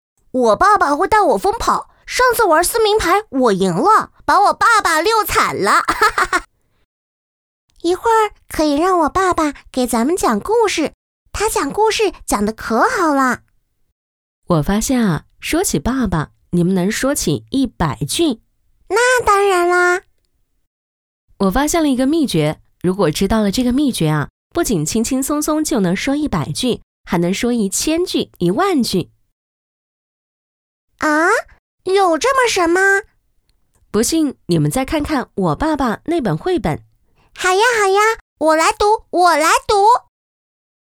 国语配音
可录台湾腔